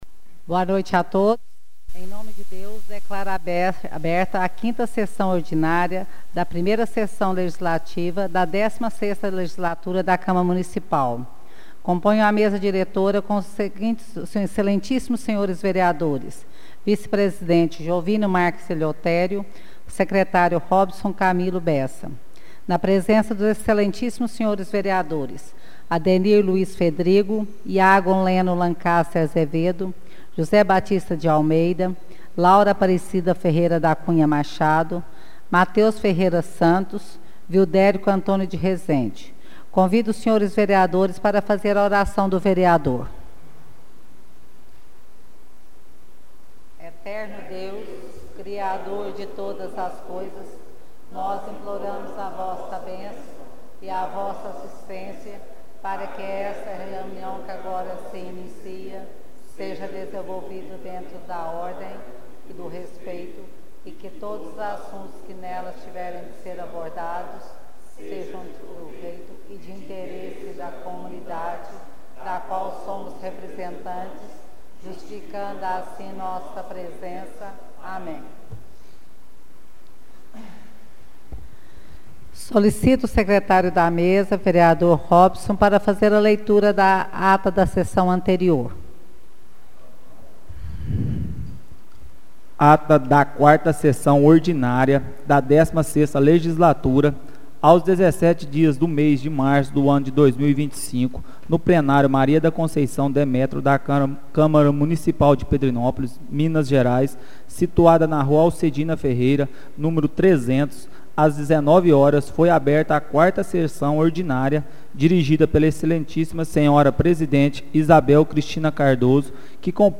Áudio da 5ª Sessão Ordinária de 2025